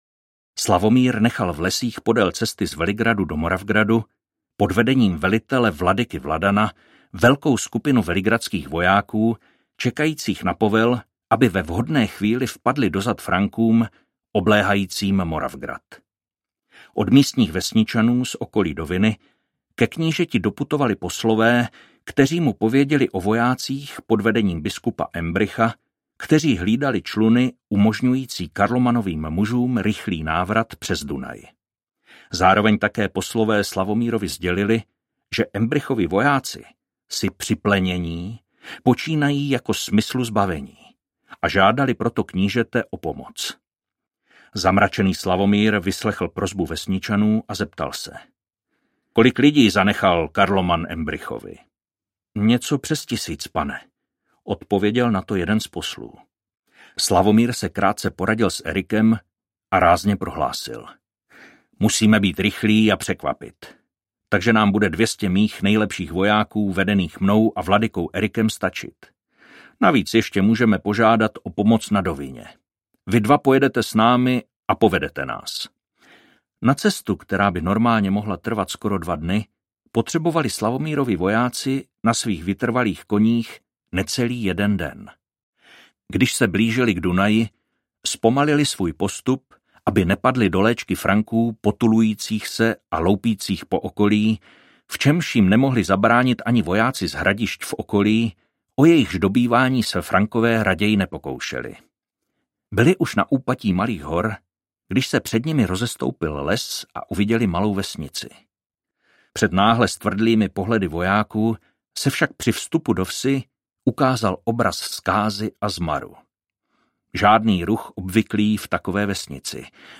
Případ forchheimského rádce audiokniha
Ukázka z knihy